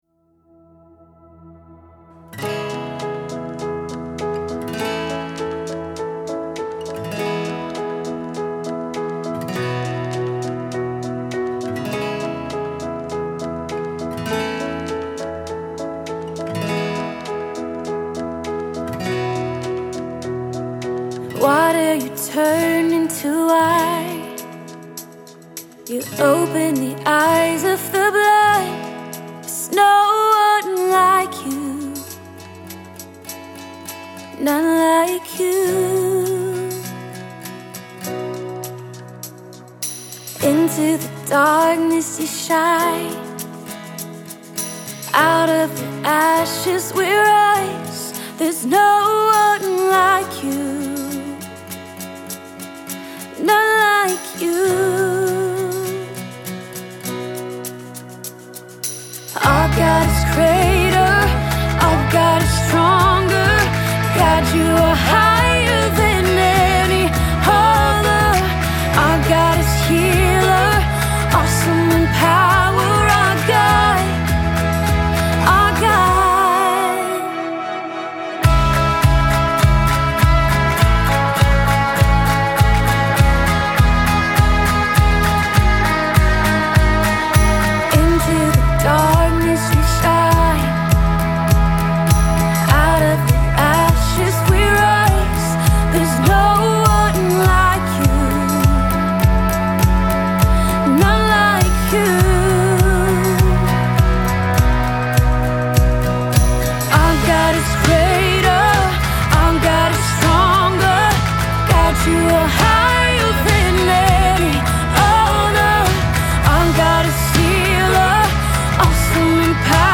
Full arrangement demo
• Tempo: 101 bpm, 4/4 time
STYLE: ORGANIC
• Drums
• Percussion
• Acoustic Guitar
• Piano
• Electric Guitar (2 tracks)
• Bass
• Pads
• BGVs